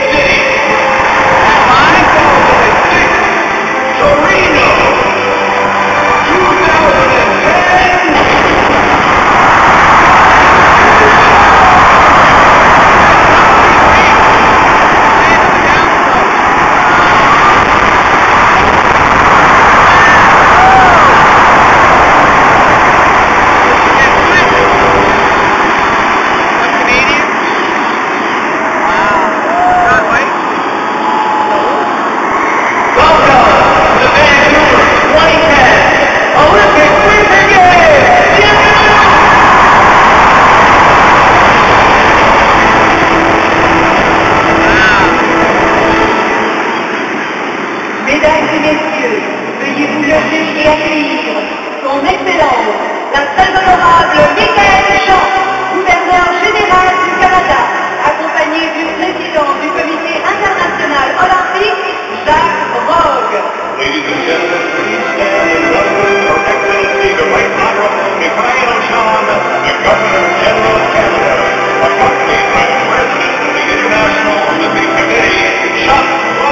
Olympic opening ceremony Vancouver
97784-olympic-opening-ceremony-vancouver.mp3